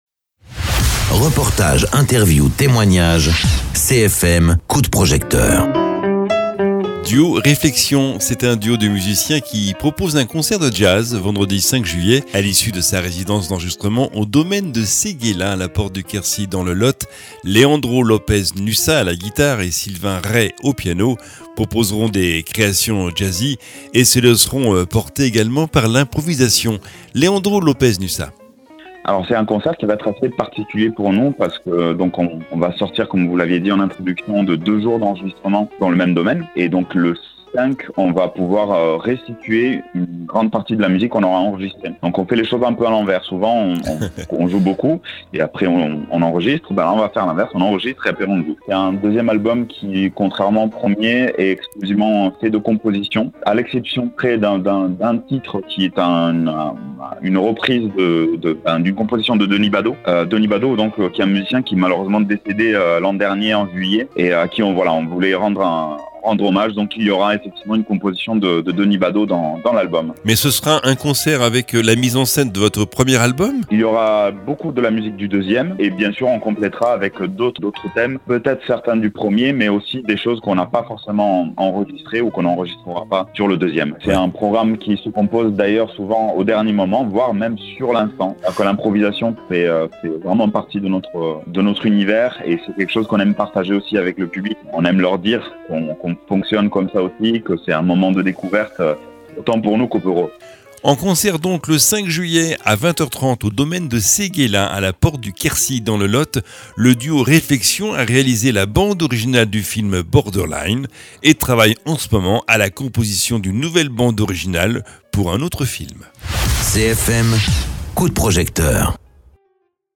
Interviews
guitariste